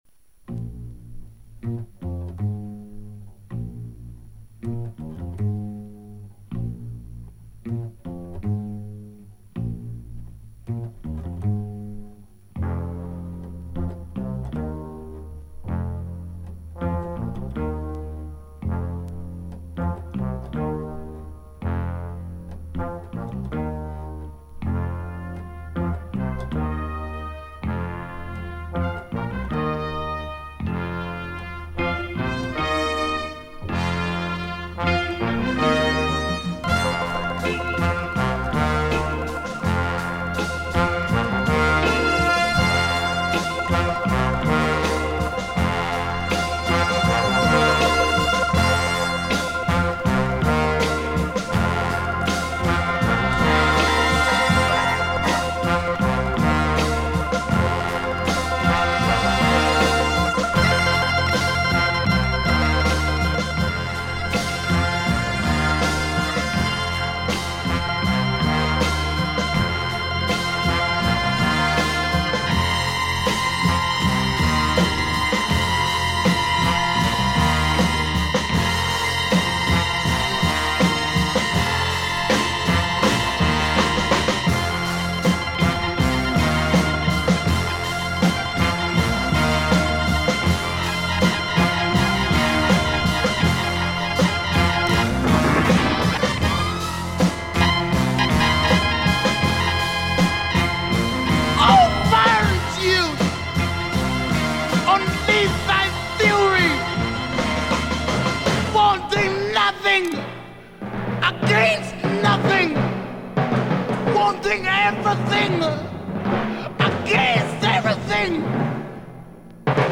slow paced psychedelic groove with crazy organ.